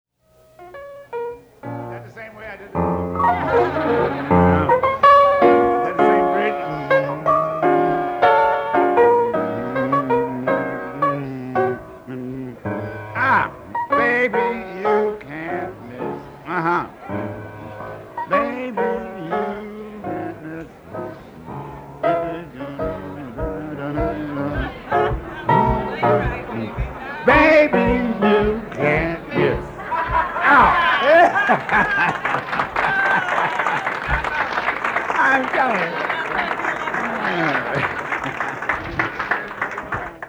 1971 Berklee College of Music Commencement, Excerpt 08 | Berklee Archives